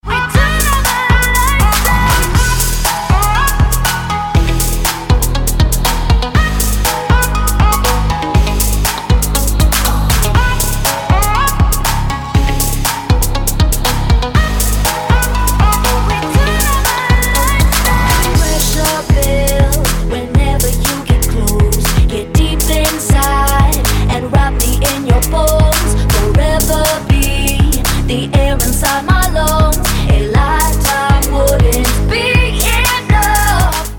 • Качество: 192, Stereo
красивые
женский вокал
Synth Pop
dance
Electronic